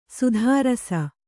♪ sudhārasa